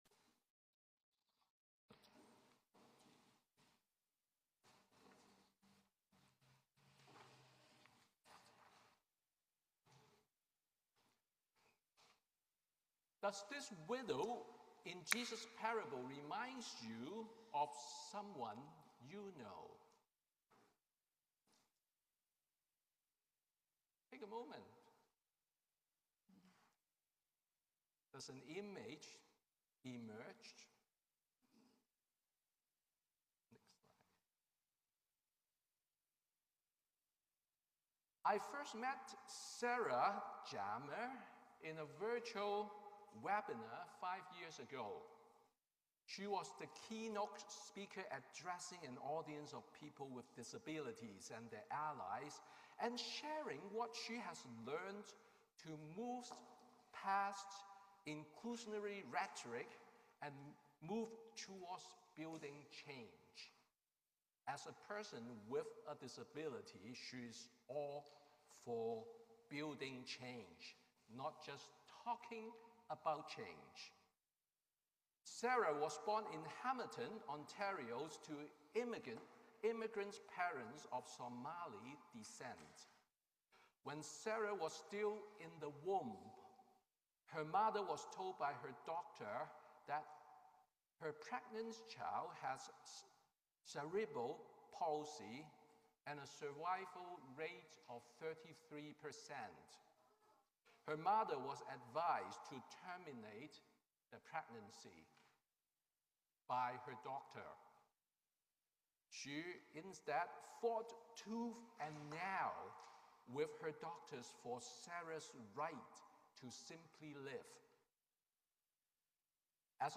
Sermon on the 19th Sunday after Pentecost